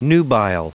Prononciation du mot nubile en anglais (fichier audio)
Prononciation du mot : nubile